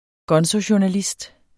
Udtale [ ˈgʌnso- ]